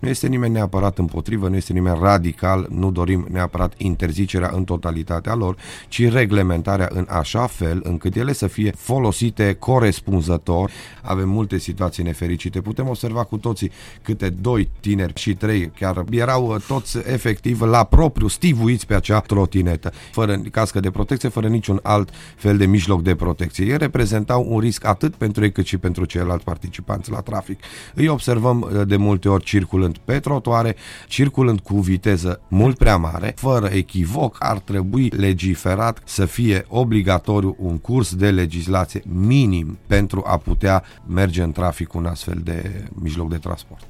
Invitat în Matinalul de la Radio Cluj